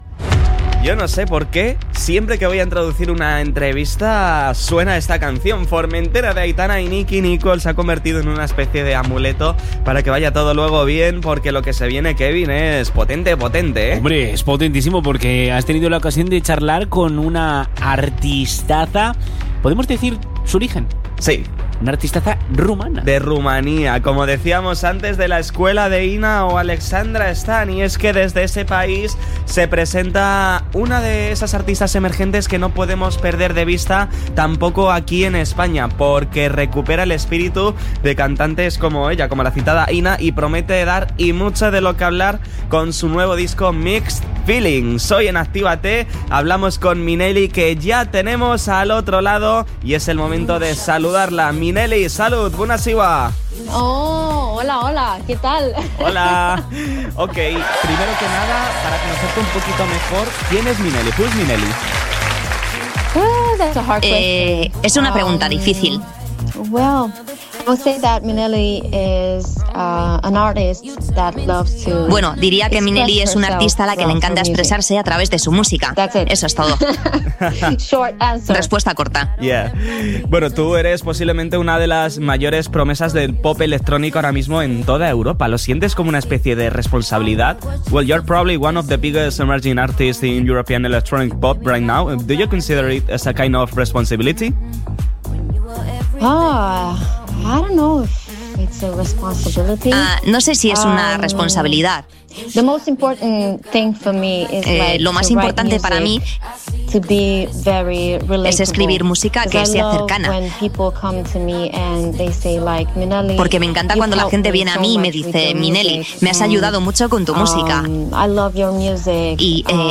ENTREVISTA-MINELLI-ACTIVATE.mp3